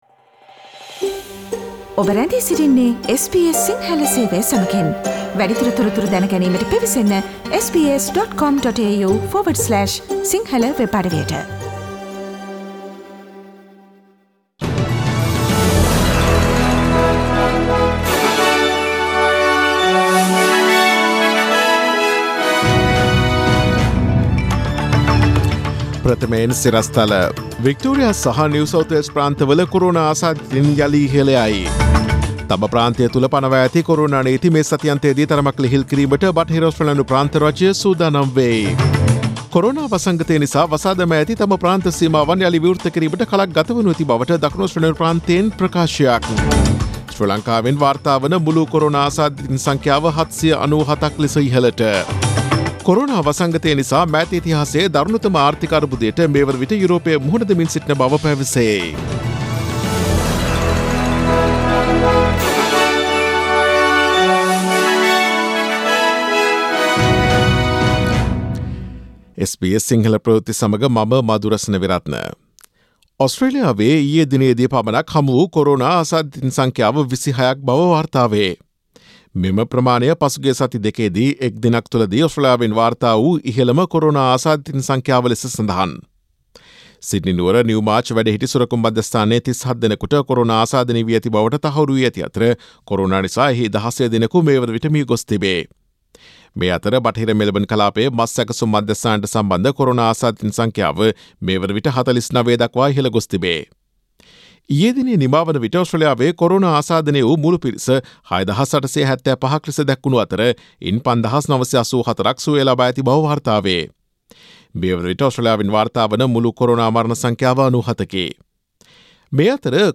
Daily News bulletin of SBS Sinhala Service: Thursday 07 May 2020